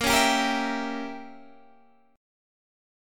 A#dim7 Chord
Listen to A#dim7 strummed